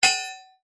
Sword Hit C.wav